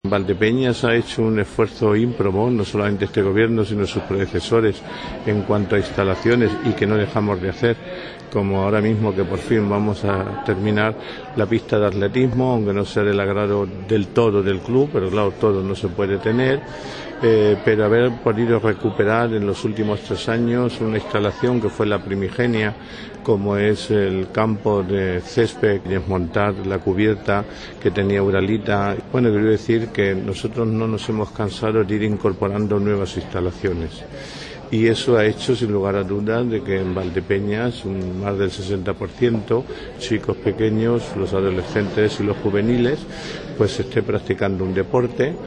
En declaraciones a los medios de comunicación, el regidor municipal ha señalado que este acto es un “pequeño reconocimiento” a los éxitos cosechados por los deportistas de la localidad y ha puesto en valor las instalaciones deportivas de la ciudad, “porque no son un gasto, sino una inversión”, ha enfatizado.